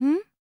TALK 1.wav